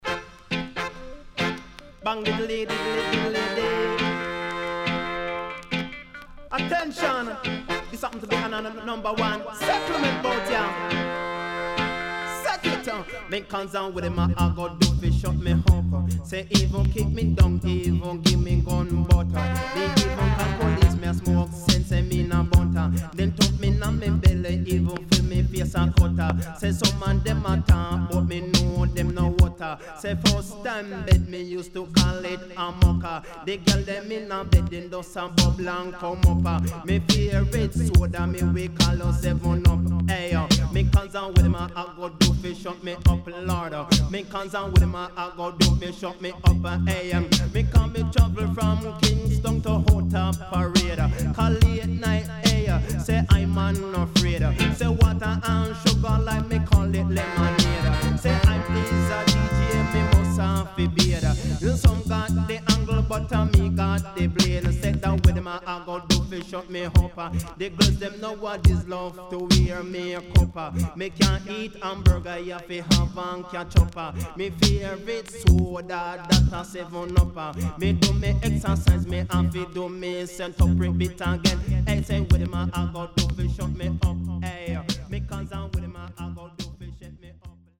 HOME > DISCO45 [DANCEHALL]
riddim
SIDE A:少しチリノイズ、プチノイズ入ります。